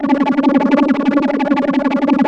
3100 FFA C#5.wav